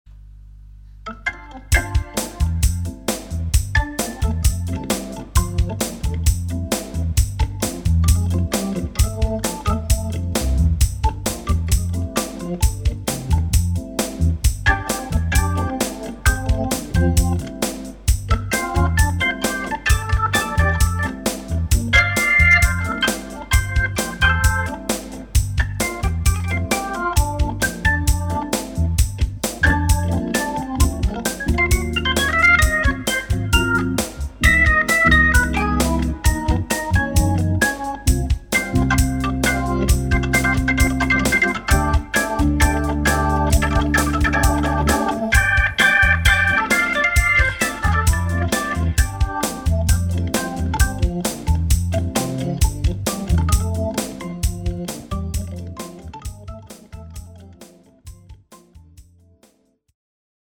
J'ai vu que j'avais quelques saturations malheureusement.
je baissrai un poil d'aigu sur l'orgue ( au dela de 8500 hz) et je baisserai les basses de 3 db....pour le reste ça roule......si on pouvait avoir sans la BAR :) :) :)
C'est la percu qui est trop incisive ? la pissette ?
L'orgue est très présent dans l'aigue, mais j'aime bien, ca rend bien sur ce genre musical.